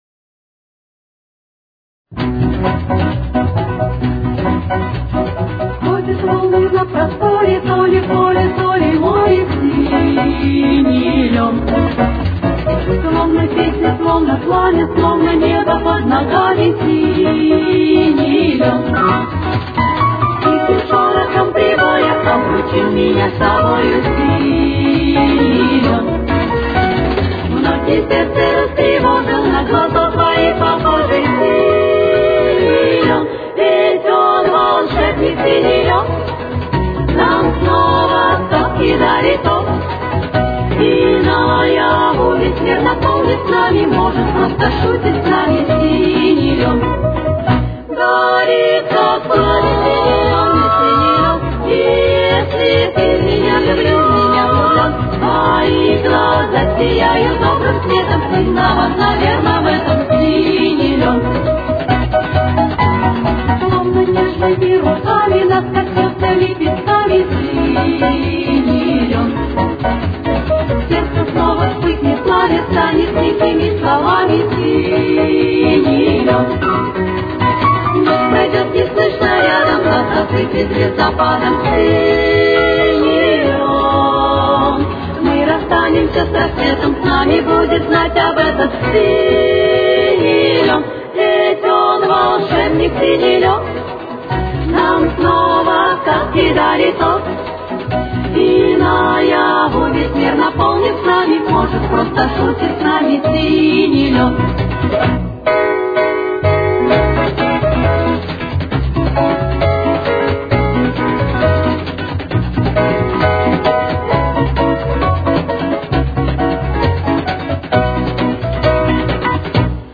Тональность: Си мажор. Темп: 68.